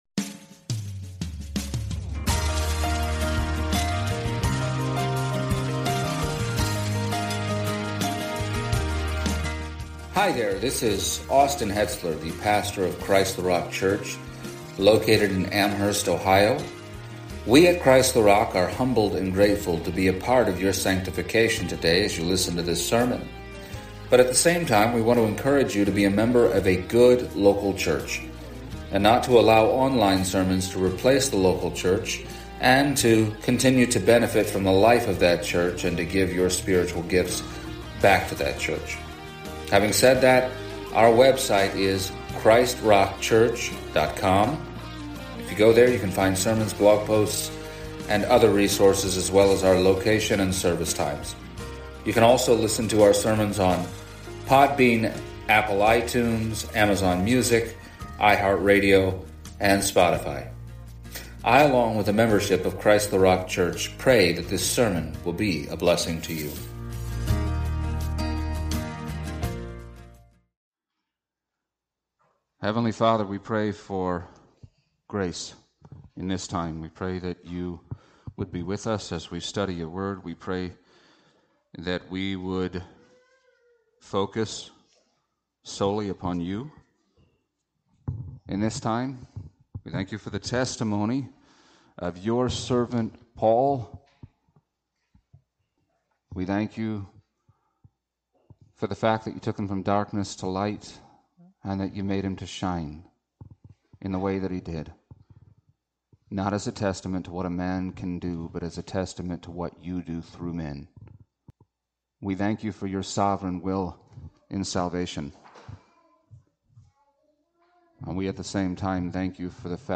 Passage: Acts 28:23-31 Service Type: Sunday Morning